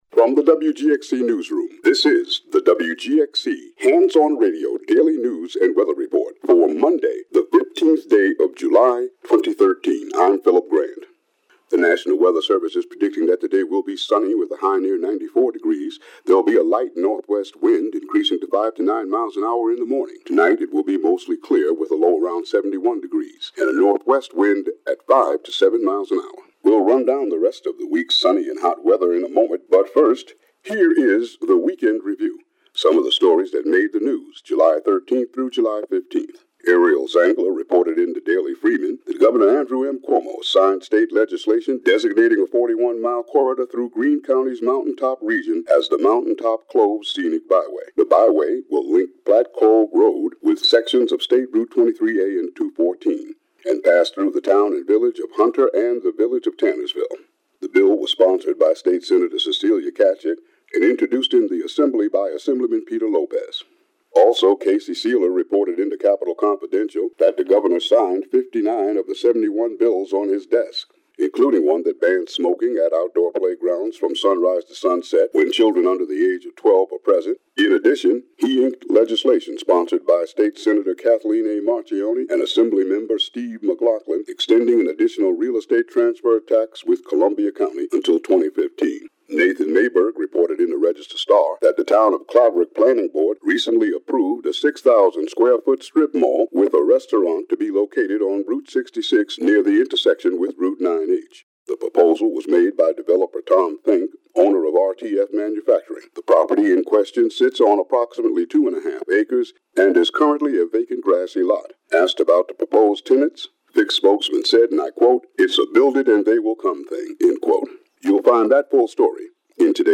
WGXC Morning Show Contributions from many WGXC programmers.
Local news and weather for Monday, July 15, 2013.